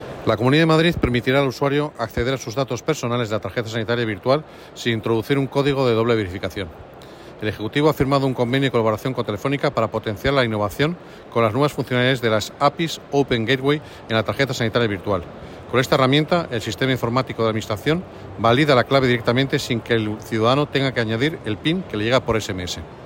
Declaraciones del consejero en la página web de la C. de Madrid con la nota de prensa]